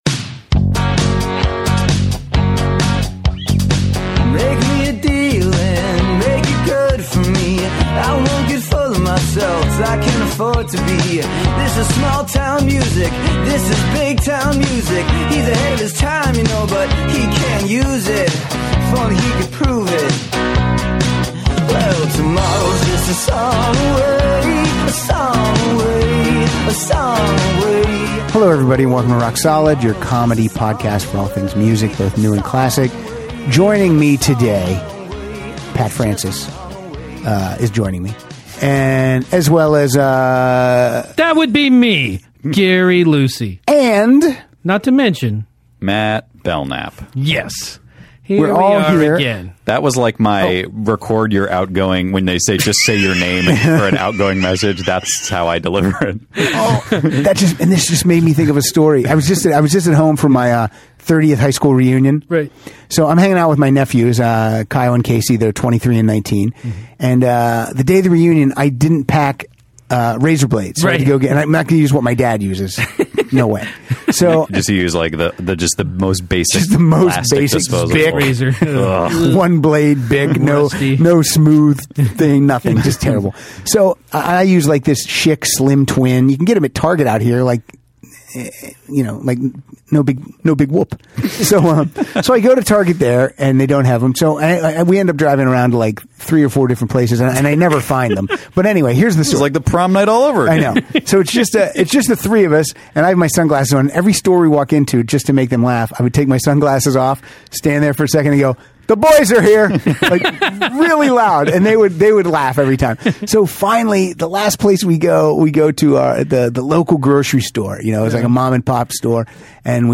playing their favorite songs with a dude's name in the title.